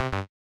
test_sound_mono.ogg